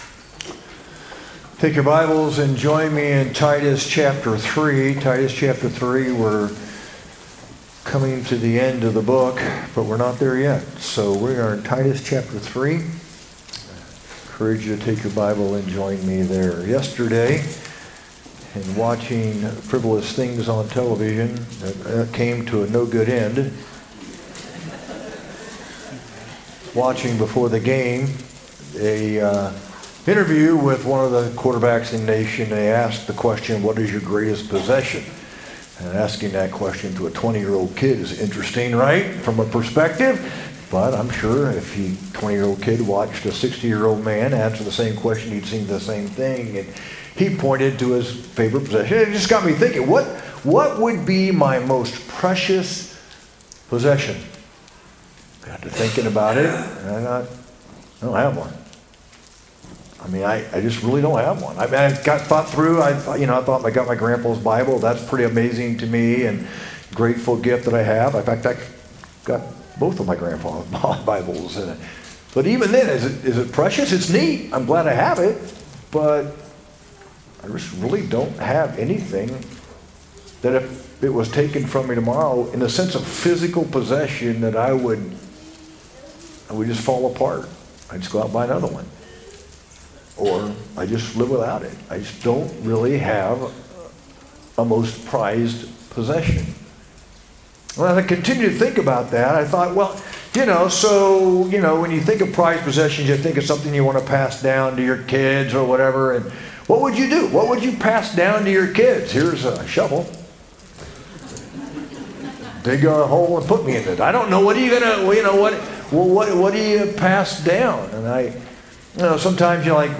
sermon-9-21-25.mp3